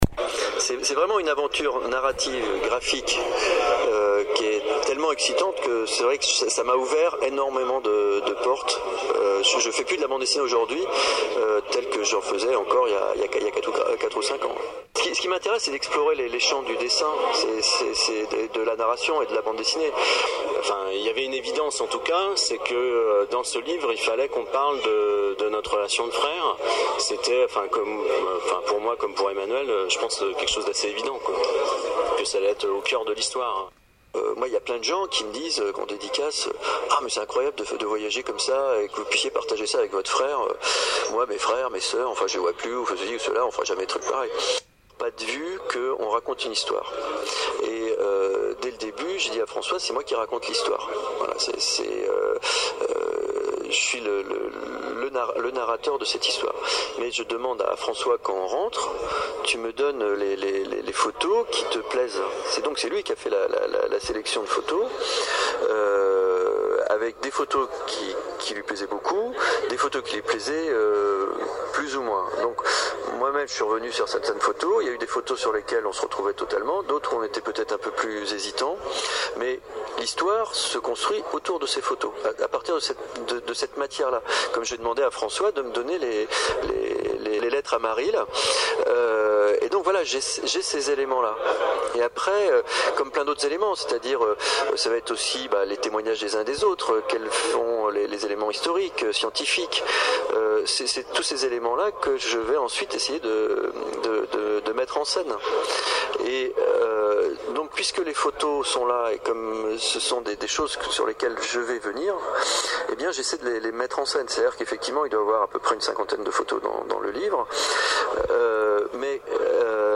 Voici leurs deux voix qui racontent comment ils ont vécu ce voyage exceptionnel et comment ils ont imaginé ce beau livre.